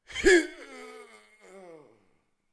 battlemage_die1.wav